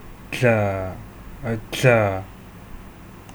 File:Voiced alveolar lateral affricate.ogg
English: Voiced alveolar lateral affricate